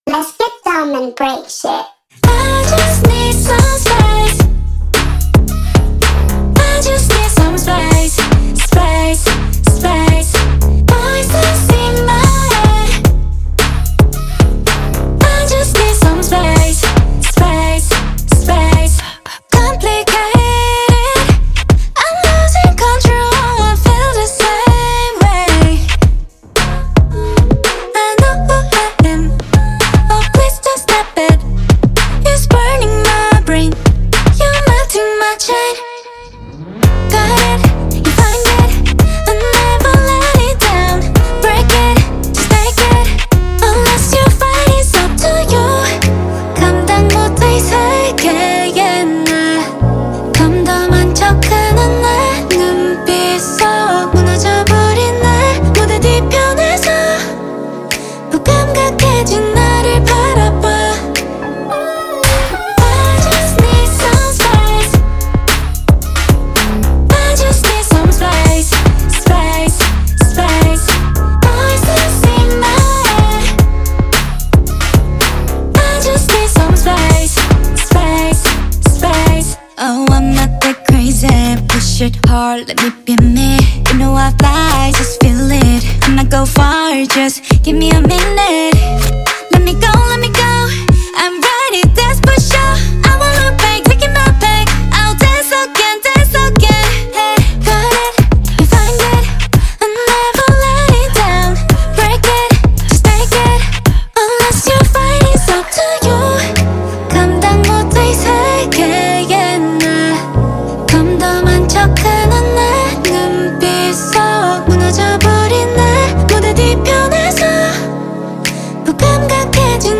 KPop Song
Label Pop